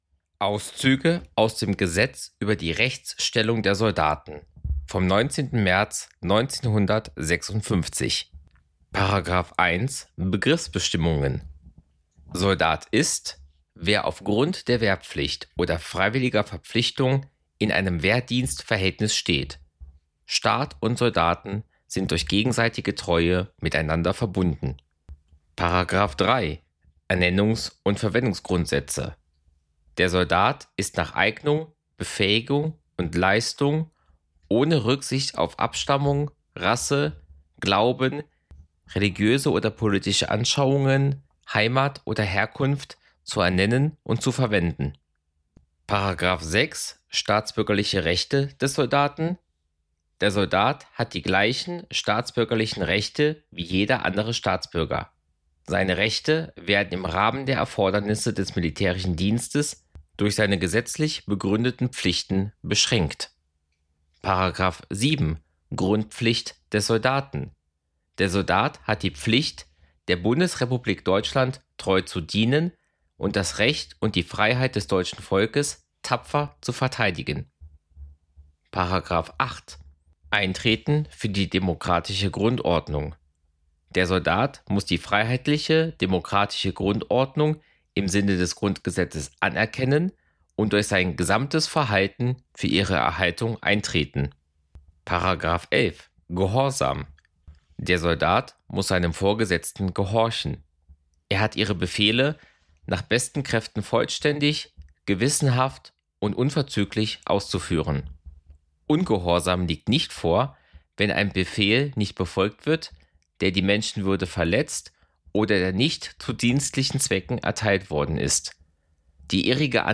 Ein Podcast über die Geschichte Europas. Gespräche mit Expert:innen, angefangen beim geologischen Entstehen der europäischen Halbinsel bis hin zum heutigen Tag.